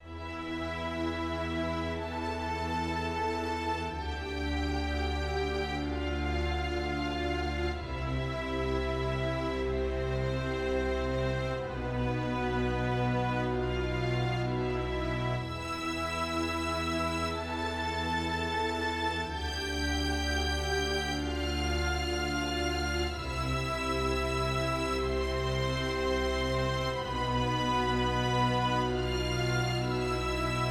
Edirol弦乐
Tag: 125 bpm Classical Loops Strings Loops 5.17 MB wav Key : E FL Studio